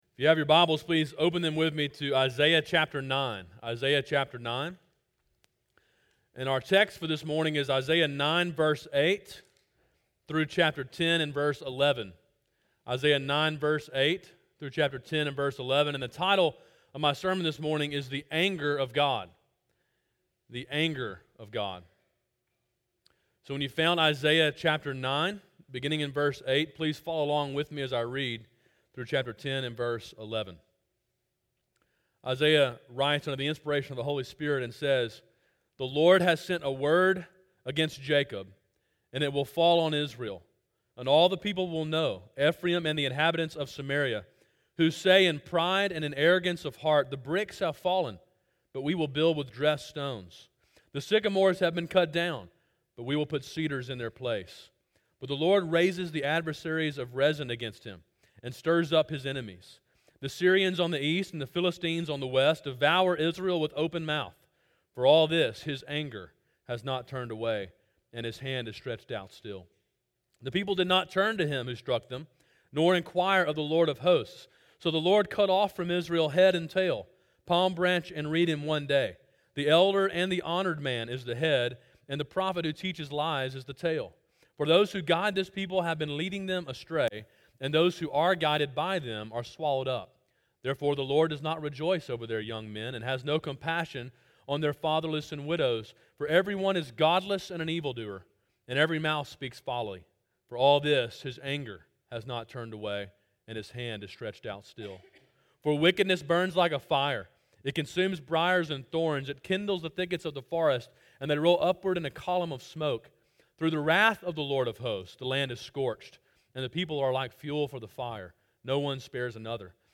Sermon in a series on the book of Isaiah.